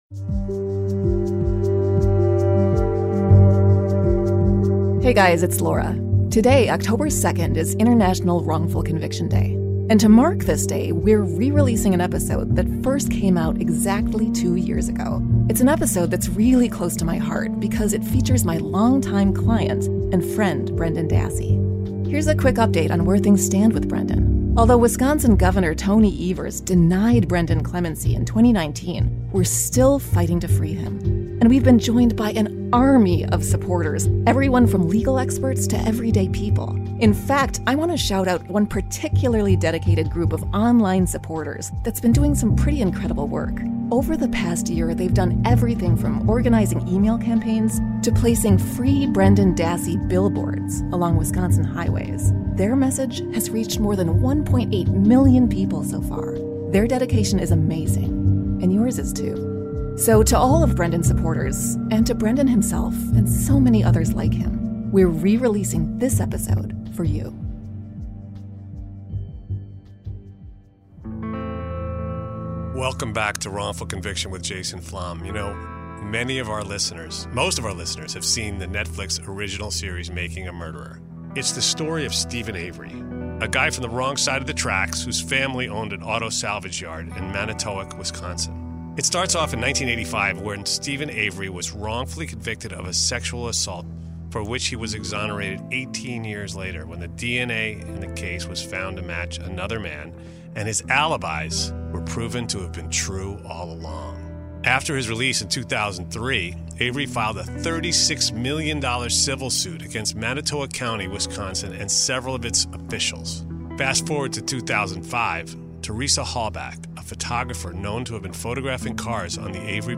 In this episode, Laura Nirider, acclaimed attorney and host of Wrongful Conviction: False Confessions, arranges Brendan Dassey's only interview from behind bars with Jason Flom.